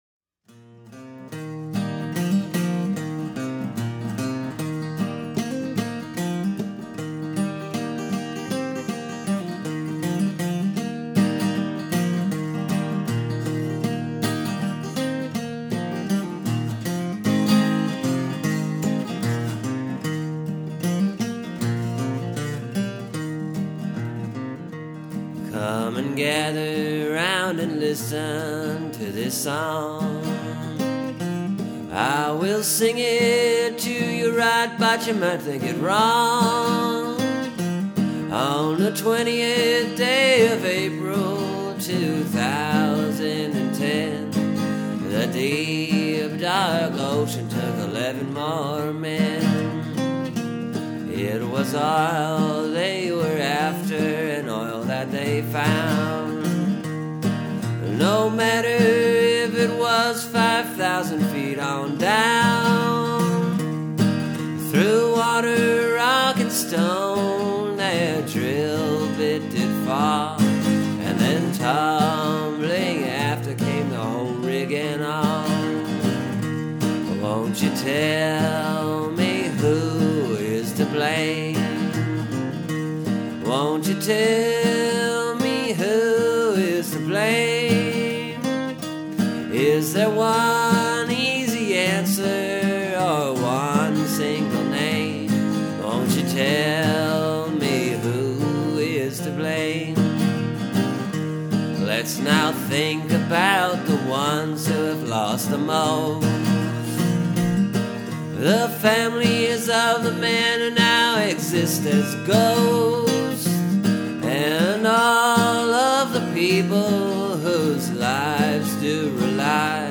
This time I used “Sowin’ on the Mountain,” which is actually a combination of a few different Carter Family tunes that uses a melody that they used a lot.